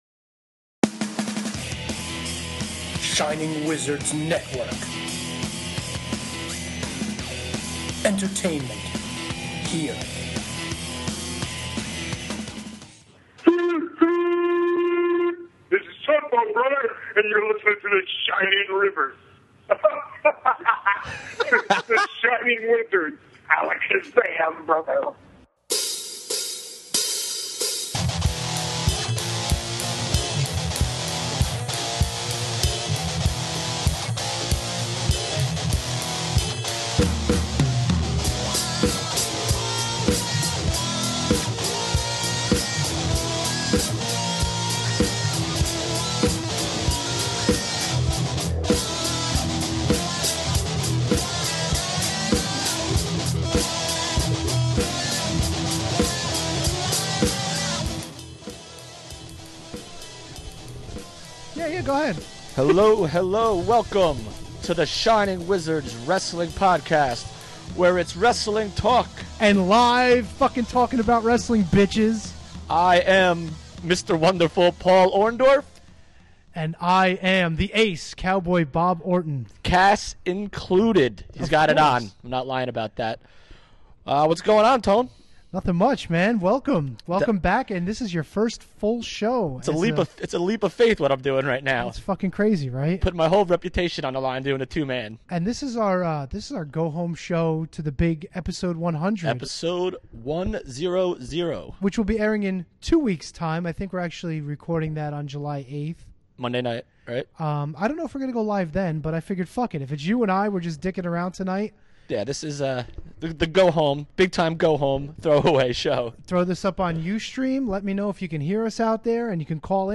Plus, your calls!